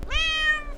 cat-meow.wav